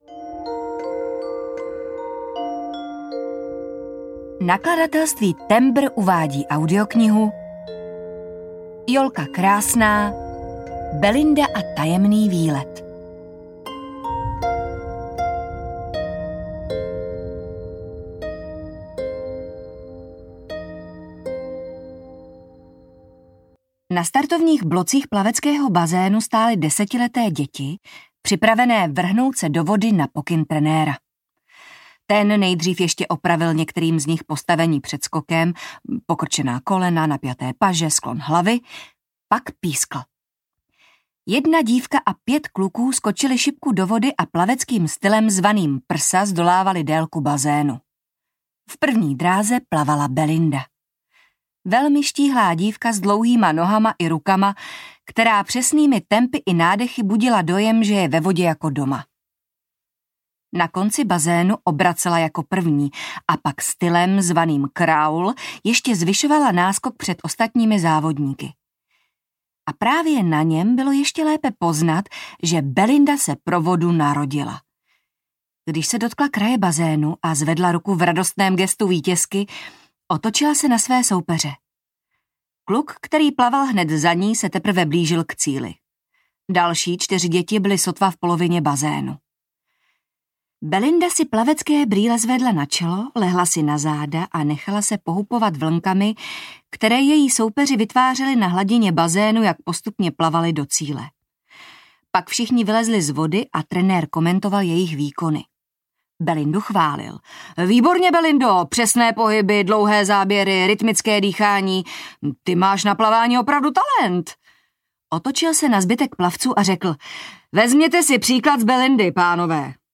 Audio knihaBelinda a tajemný výlet
Ukázka z knihy